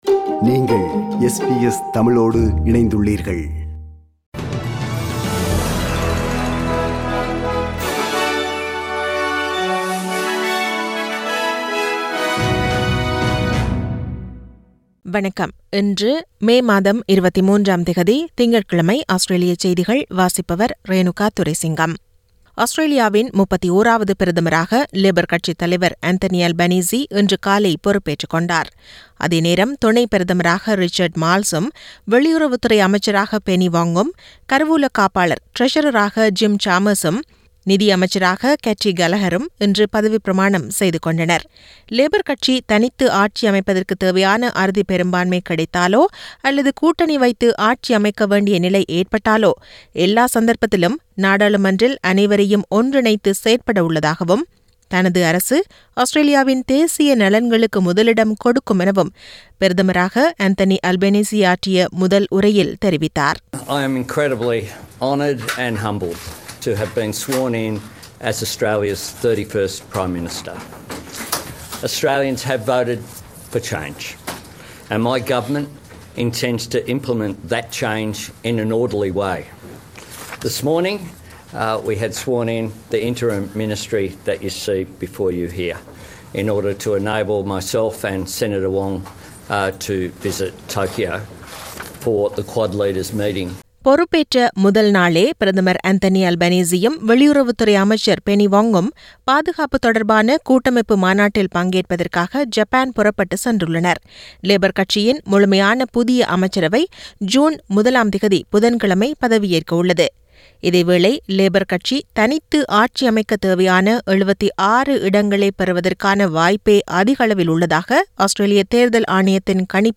Australian news bulletin for Monday 23 May 2022.